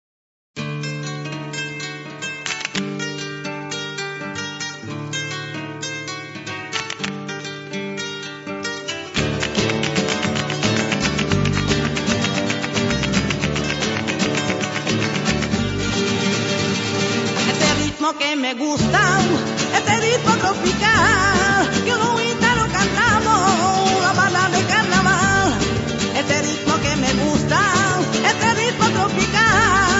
• flamenco
• musica zigana
• registrazione sonora di musica